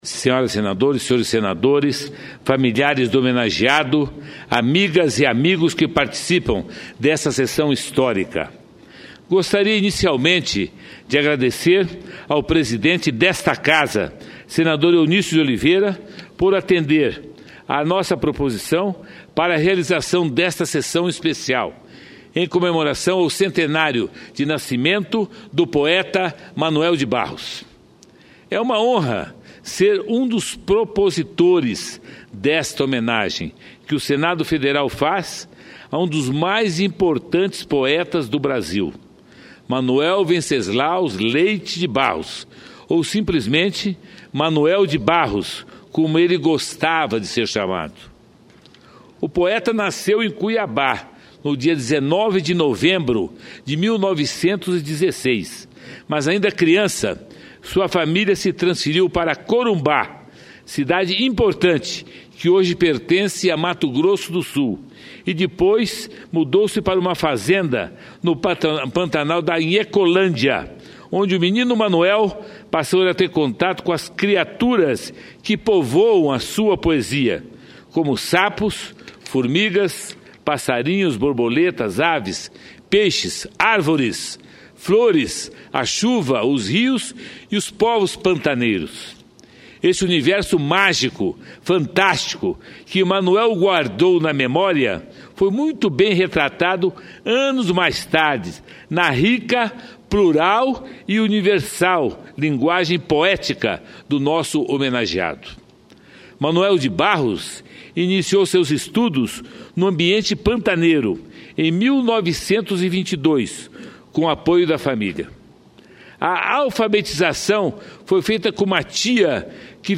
Sessão Especial
Pronunciamento do senador Pedro Chaves